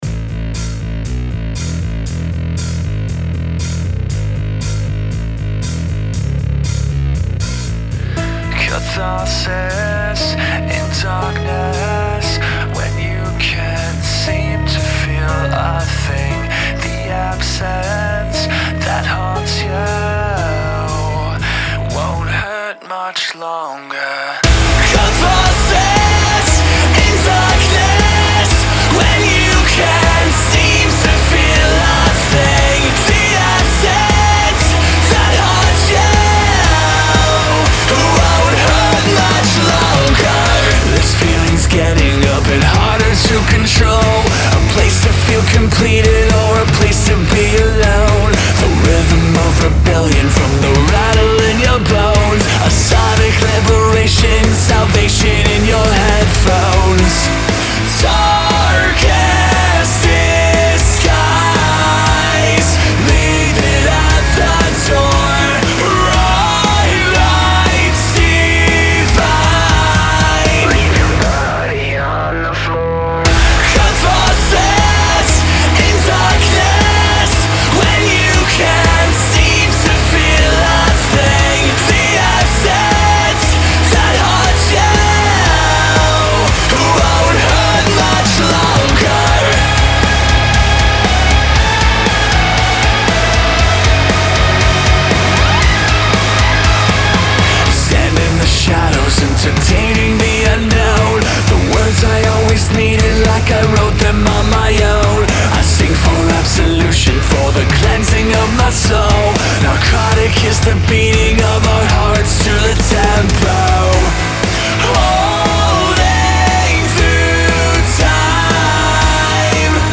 Genre : Rock, Metal